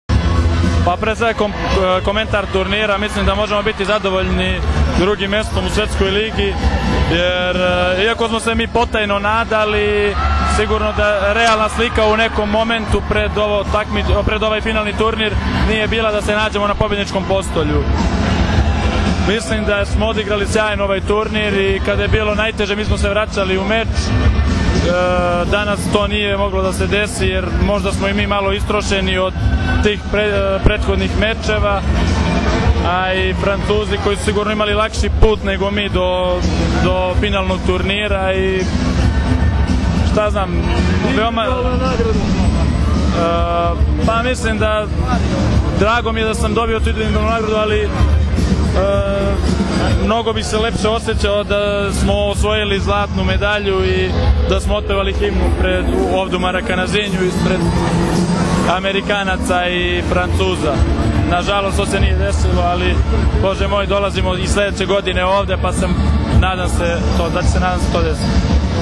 IZJAVA SREĆKA LISNICA